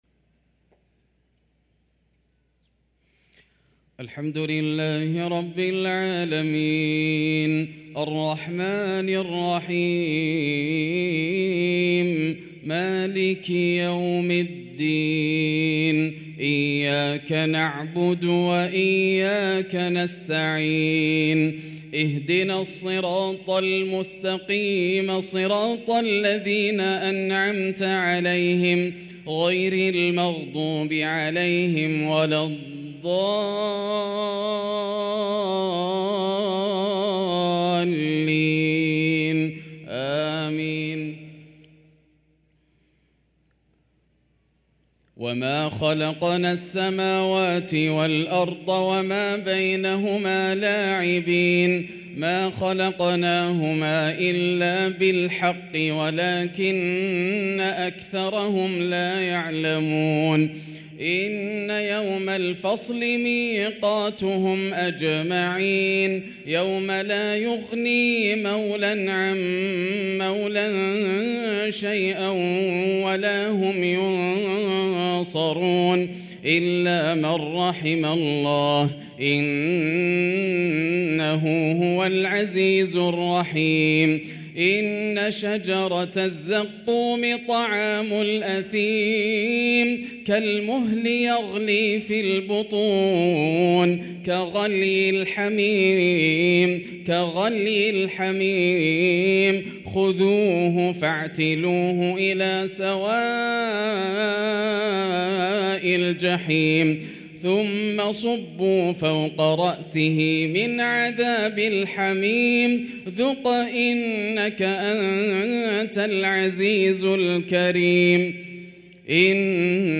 صلاة العشاء للقارئ ياسر الدوسري 25 محرم 1445 هـ